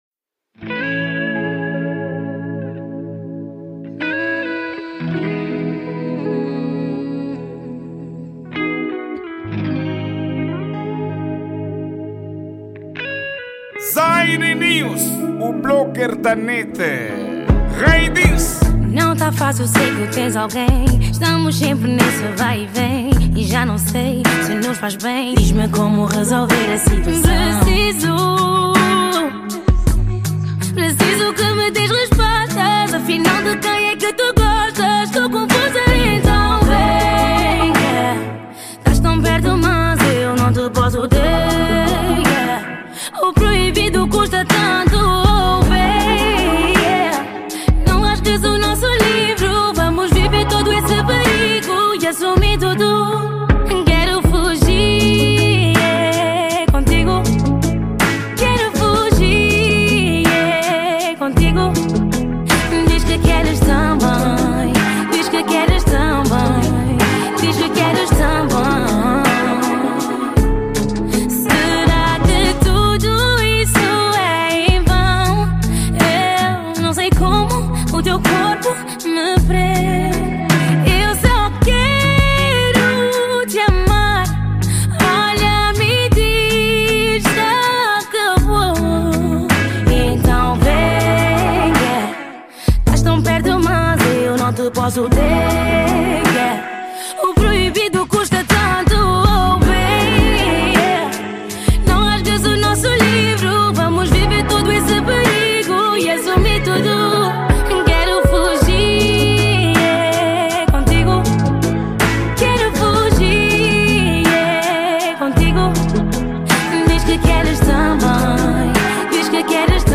Gênero: R&B
Afro Pop